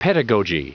Prononciation du mot pedagogy en anglais (fichier audio)
Prononciation du mot : pedagogy